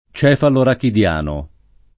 cefalorachideo [ ©H falorakid $ o ]